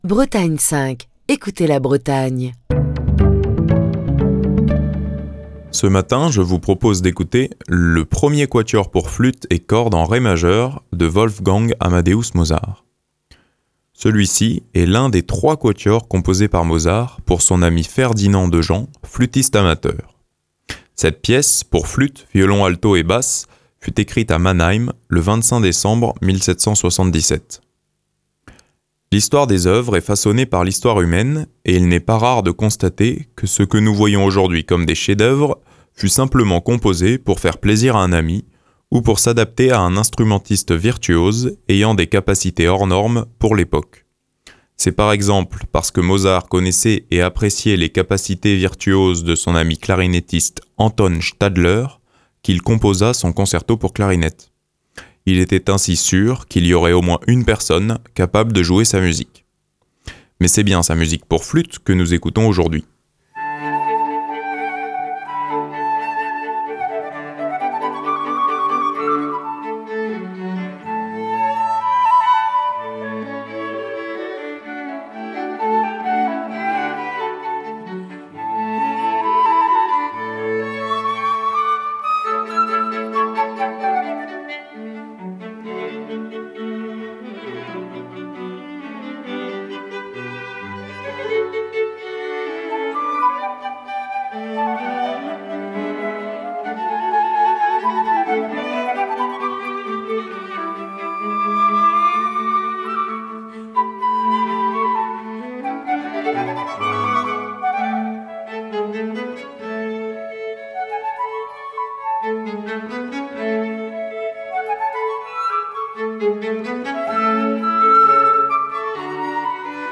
Cette pièce pour flûte, violon, alto et basse fut écrite à Mannheim le 25 décembre 1777.
Fil d'Ariane Accueil Les podcasts Le Quatuor n° 1 pour flûte et cordes en ré majeur de Wolfgang Amadeus Mozart Le Quatuor n° 1 pour flûte et cordes en ré majeur de Wolfgang Amadeus Mozart Émission du 6 mars 2024.
Ce premier mouvement du quatuor pour flûte et cordes en ré majeur
quatuor à cordes français